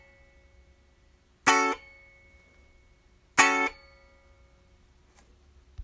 Ich stoppe die Saite (alle Saiten), aber es klingt etwas nach, ich dachte erst in den Pickups oder ein loses Kabel oder Schraube, aber nach dem Anlegen des Ohres an den Hals (Rückseite) würde ich die Schwingung im Hals vermuten - der Halsstab.
Ich habe das mal über ein Mikro aufgenommen und als mp3 beigefügt, ich hoffe man kann das hören. Eigenschaft Anhänge A13 mit Nachklang.mp3 66,3 KB · Aufrufe: 222